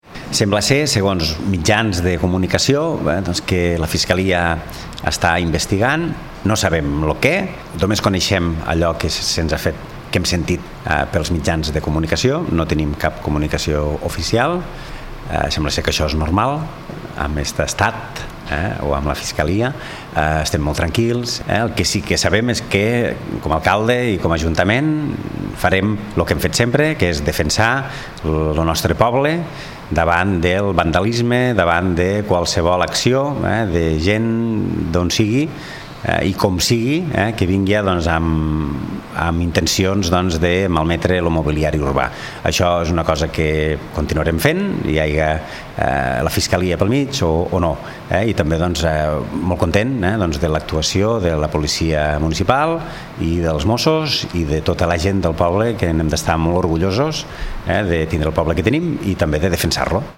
Segons Gaseni, l’Ajuntament encara no ha rebut cap informació oficial sobre aquesta investigació i assegura que es seguirà defensant el poble davant qualsevol tipus de vandalisme. Escoltem Jordi Gaseni.